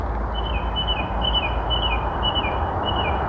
Tonaufnahme vom Gesang einer Singdrossel
BirdNET Beobachtung - Singdrossel